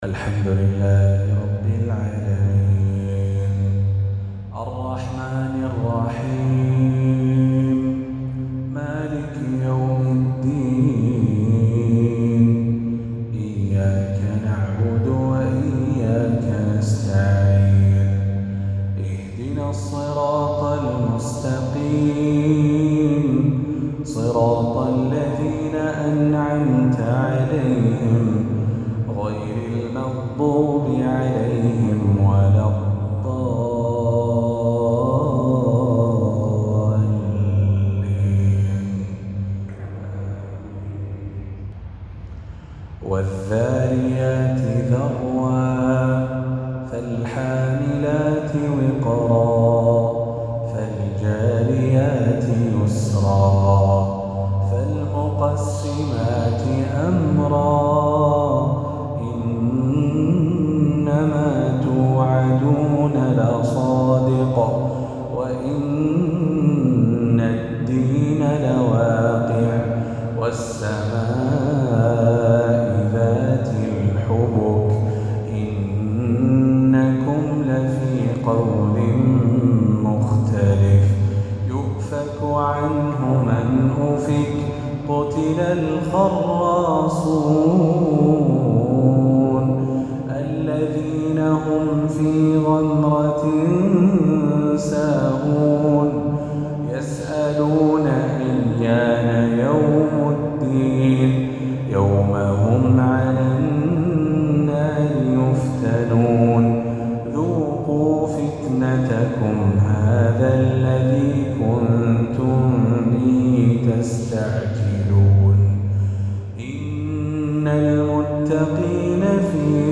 عشائية الإثنين